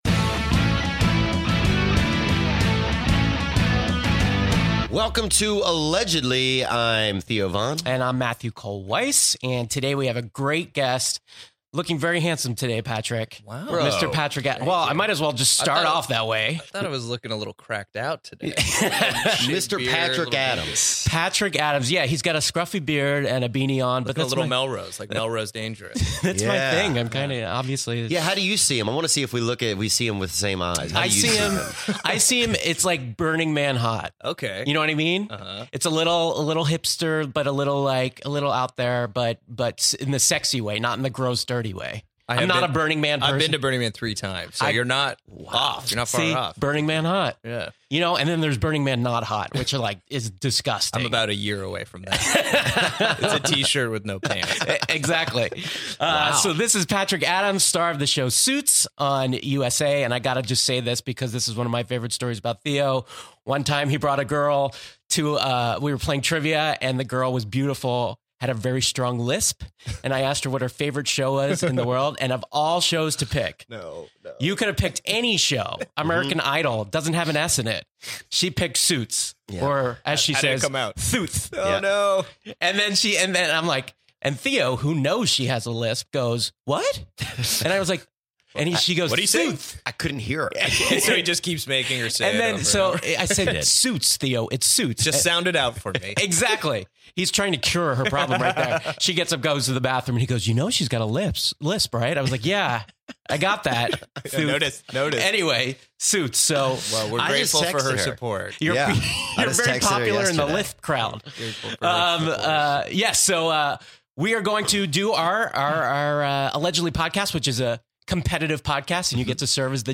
You get this week's episode of "Allegedly" starring USA's "Suits" star Patrick Adams as the guest judge and interview!